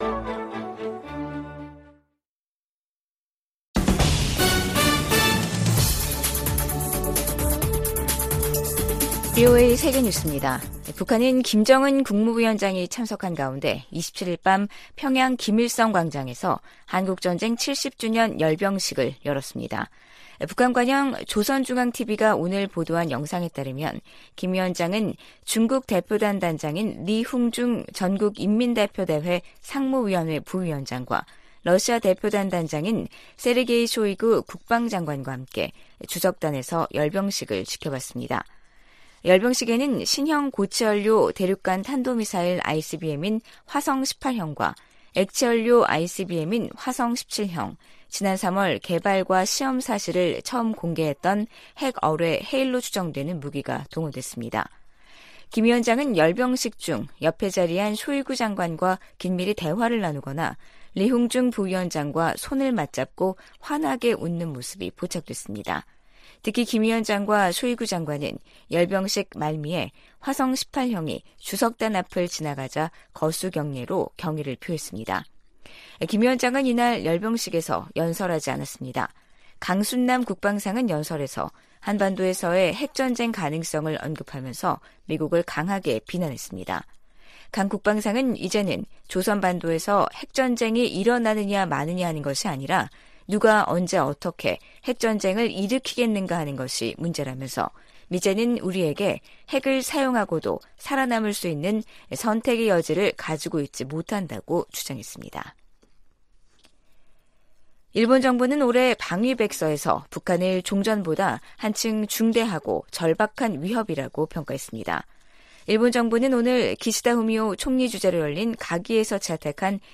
VOA 한국어 간판 뉴스 프로그램 '뉴스 투데이', 2023년 7월 28일 2부 방송입니다. 미 국무부는 러시아가 북한의 불법 무기 프로그램을 지원하고 있다고 비난했습니다. 로이드 오스틴 미 국방장관은 미한 상호방위조약이 체결된 지 70년이 지난 지금 동맹은 어느 때보다 강력하다고 밝혔습니다. 북한이 김정은 국무위원장이 참석한 가운데 '전승절' 열병식을 열고 대륙간탄도미사일 등 핵 무력을 과시했습니다.